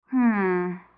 hmm2.wav